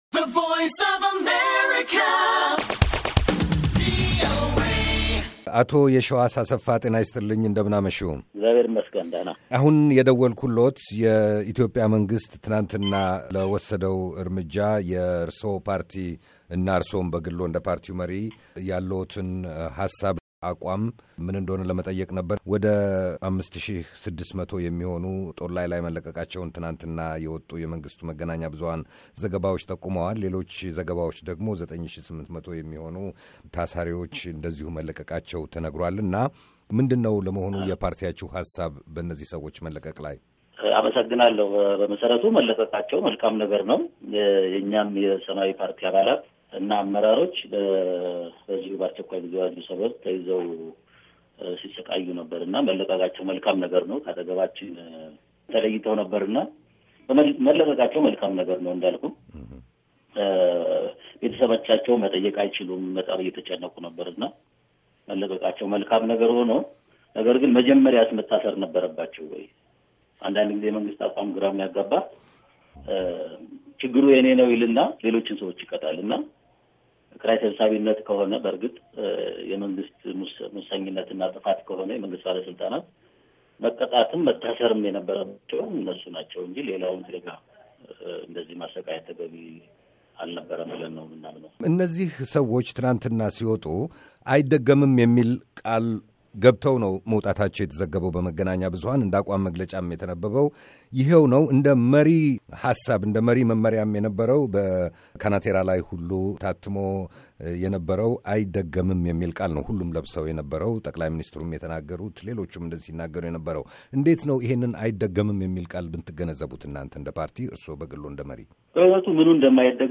ከሰማያዊ ፓርቲ ሊቀመንበር አቶ የሺዋስ አሰፋ ጋር የተደረገ ቃለ ምልልስ